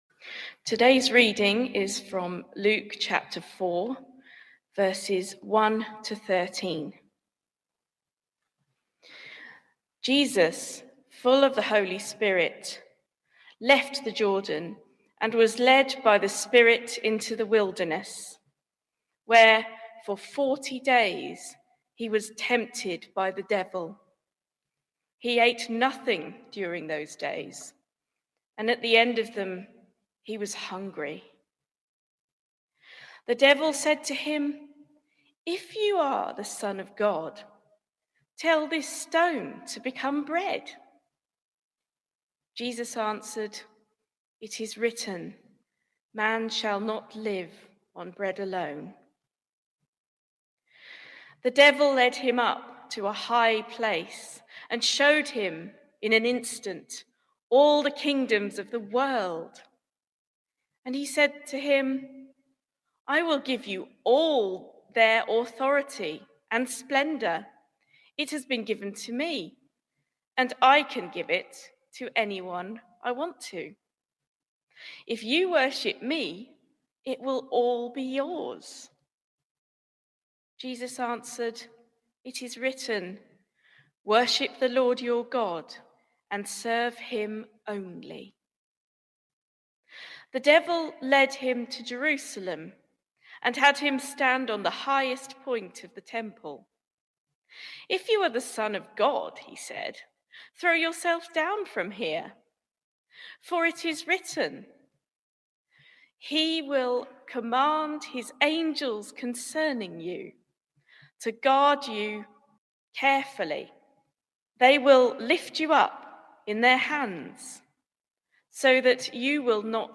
My reading of Luke 4:1-13 on 7 March 2022, the 1st Sunday of Lent